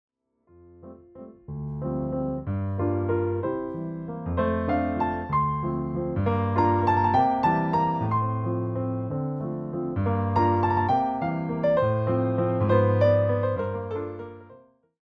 uses relaxed rubato to shape the melody.